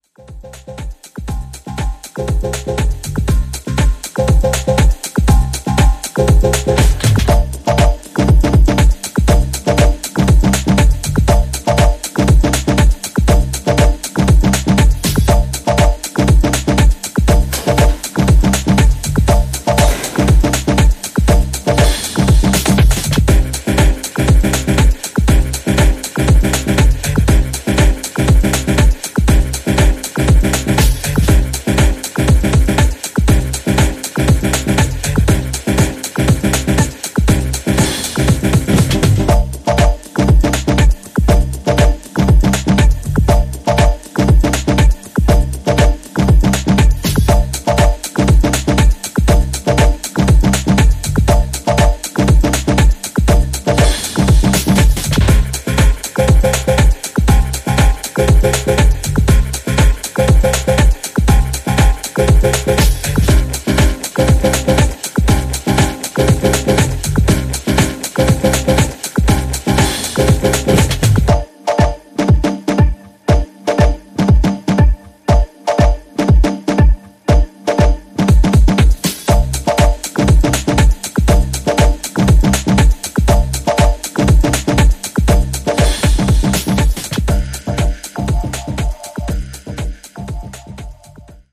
ジャンル(スタイル) TECH HOUSE / DEEP HOUSE / MINIMAL